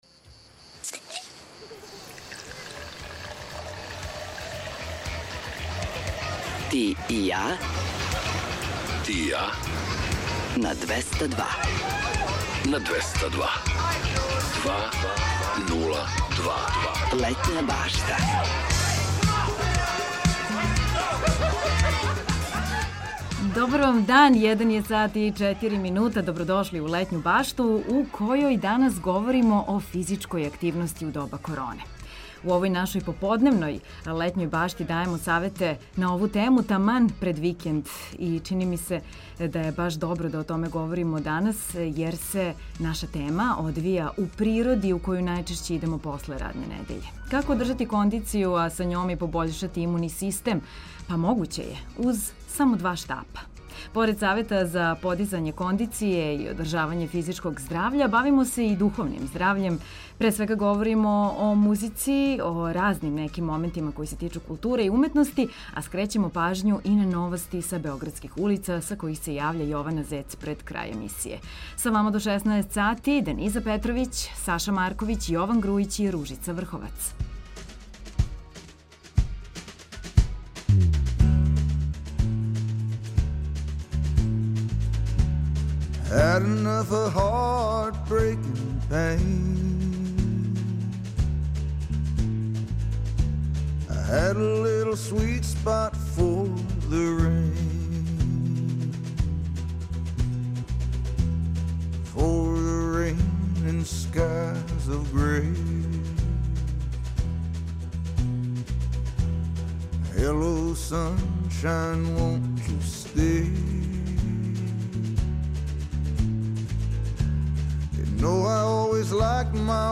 Време у великој мери посвећујемо музици, занимљивостима које су везане за данашњи датум, причама које стоје иза песама и светским топ листама.